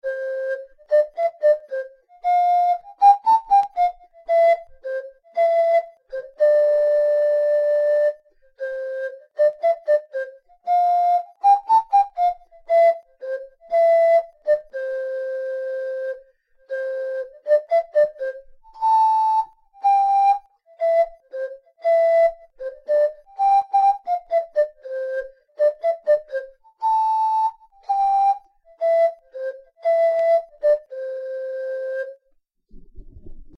bei einer Improvisation entstand dieses Lied: